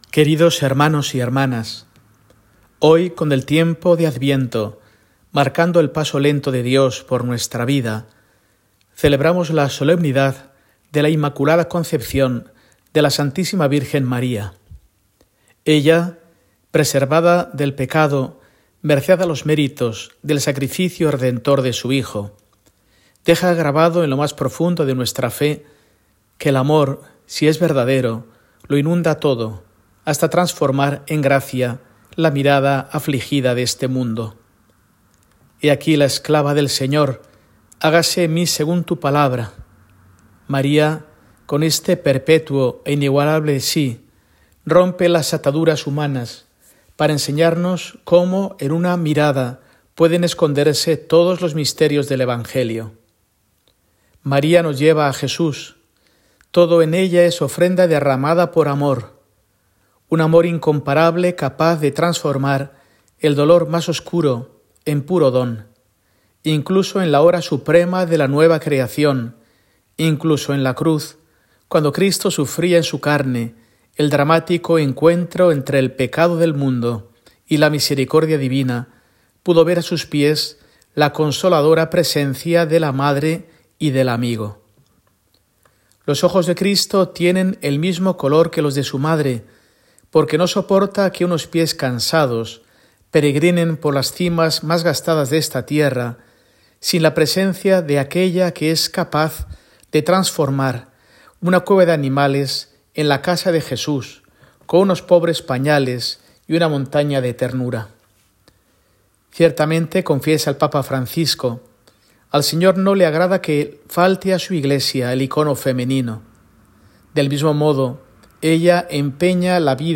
Carta semanal de Mons. Mario Iceta Gavicagogeascoa, arzobispo de Burgos, para el domingo, 8 de diciembre de 2024, solemnidad de la Inmaculada Concepción de la Virgen María